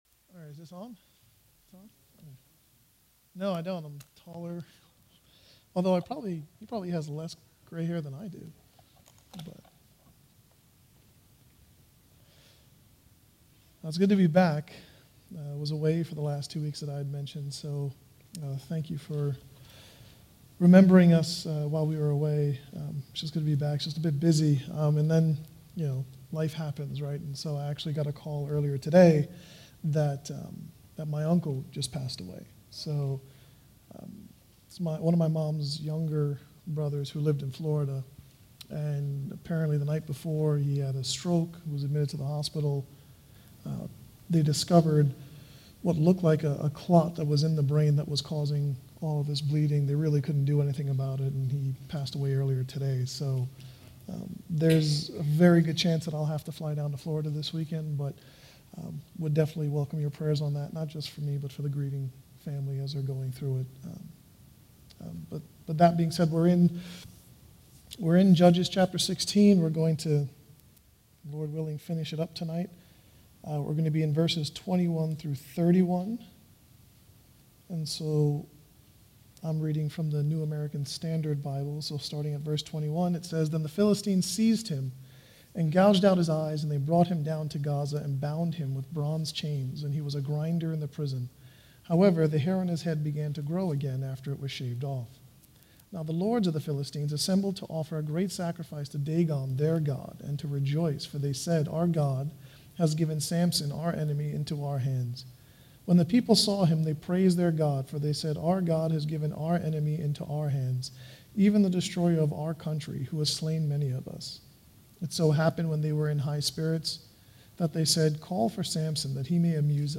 All Sermons Judges 16:21-31